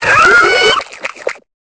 Cri de Tritosor dans Pokémon Épée et Bouclier.